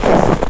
STEPS Snow, Walk 08-dithered.wav